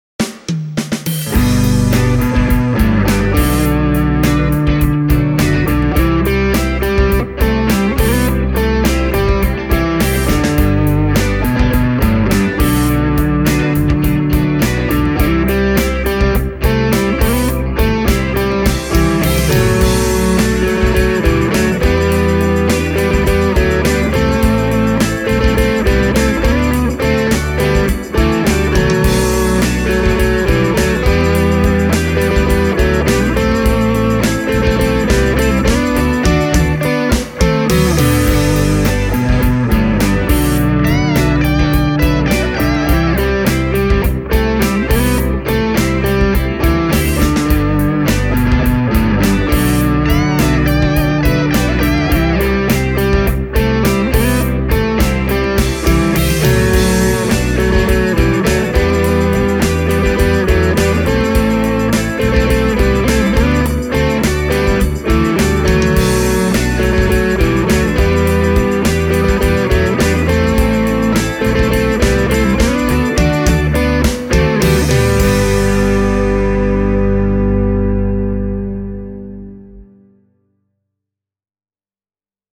The tone of the Flaxwood MTQ doesn’t come as a surprise – this model offers an array of very tasty Tele-style sounds!